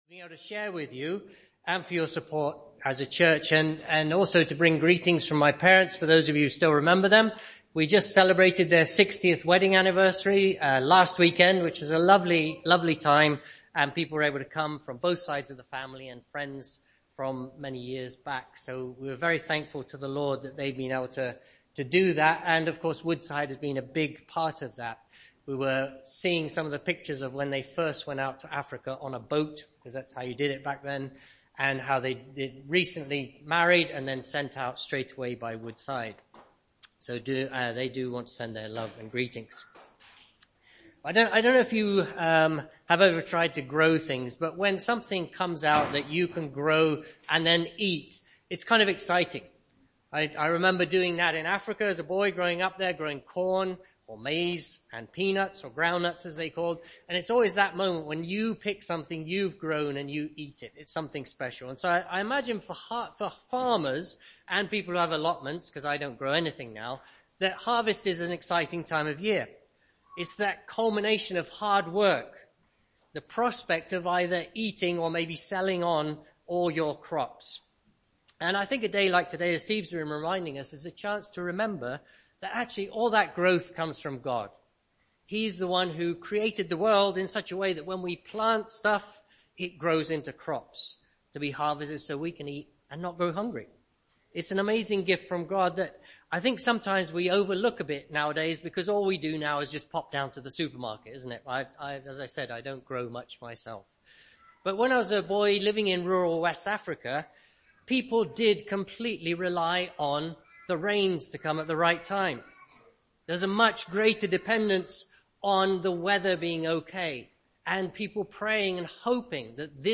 Special guest speaker of one of our missionaries for our Harvest Service.
Passage: Luke 10:1-12 Service Type: All-age worship Special guest speaker of one of our missionaries for our Harvest Service.